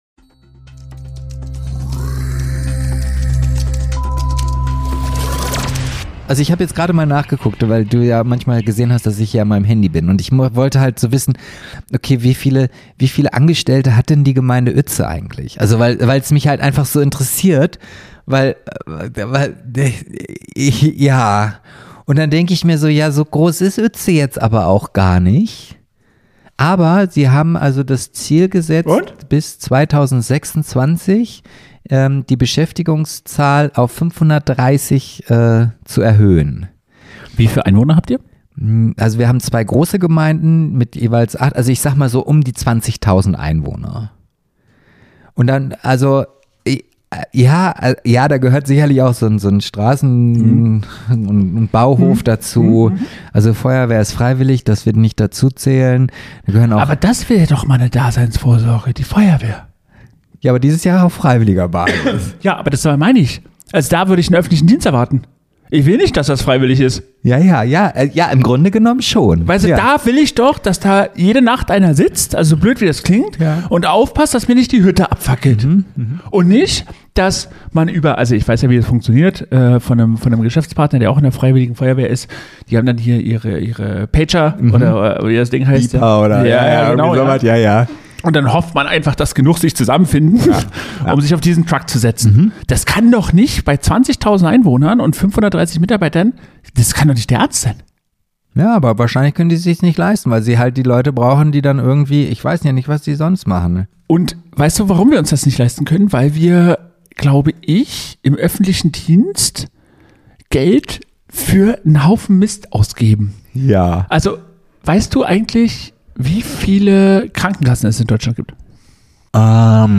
Eine ehrliche Diskussion über öffentlichen Dienst, Daseinsvorsorge, Bürokratie und die Frage: Ist das alles noch effizient – oder einfach nur Gewohnheit?